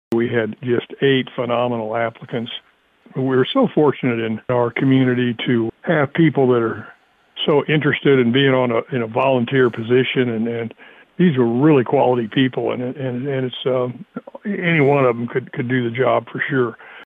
Eight people were interviewed for the opening on the Livingston County Health Center Board of Trustees.  Livingston County Presiding Commissioner Ed Douglas commented on the candidates for the opening.